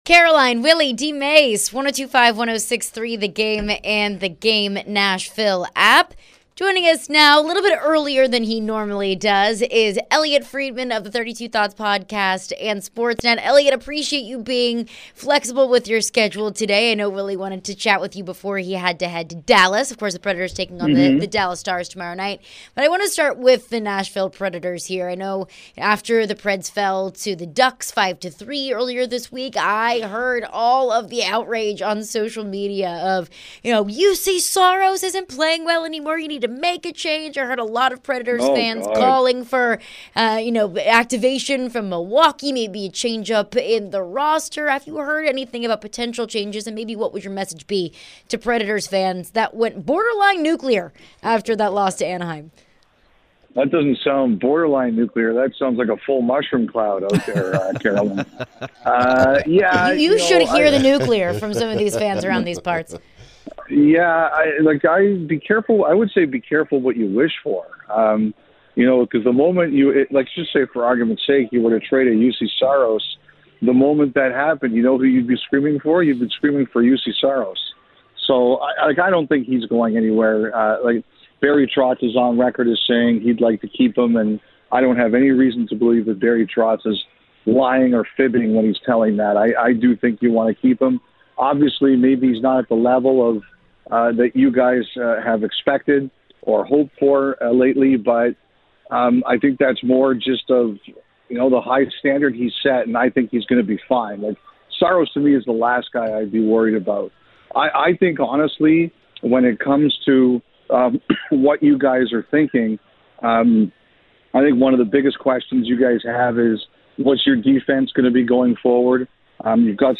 the guys talk with Elliotte Friedman at Sportsnet Hockey Night in Canada. Elliotte discusses the Nashville Predators and Juuse Saros. Elliotte shared his thoughts on the Preds and possible trade rumors.